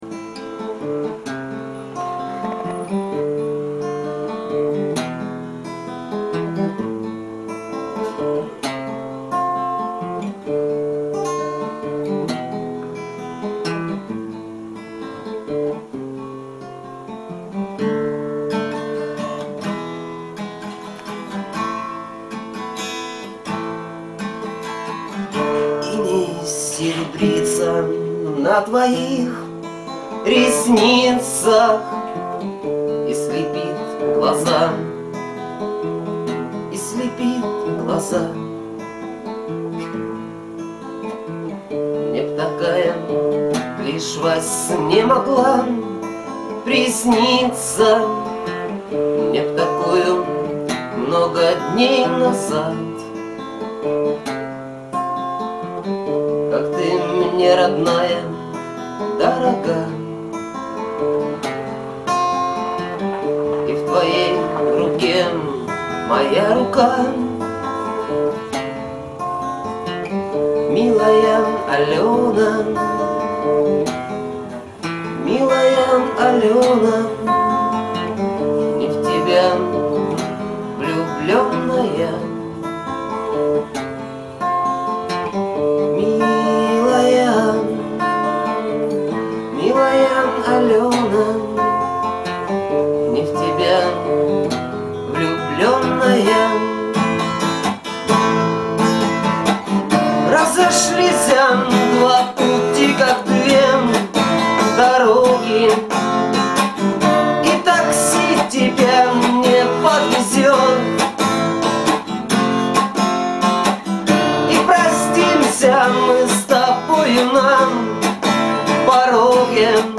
Лирические под гитару